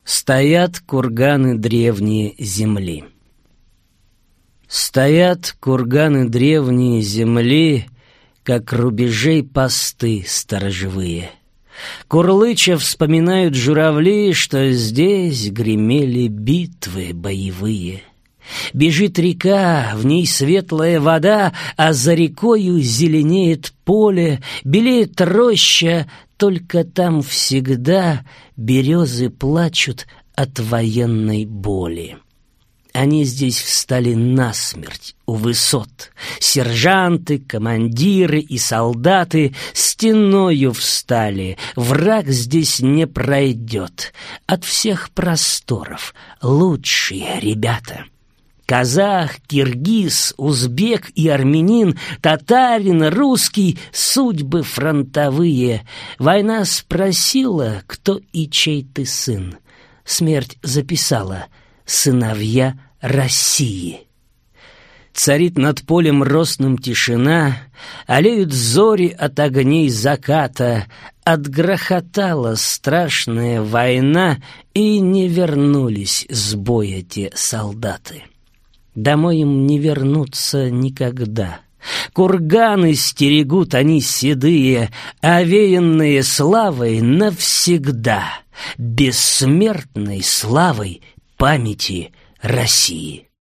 Аудиокнига Гой ты, сторонушка!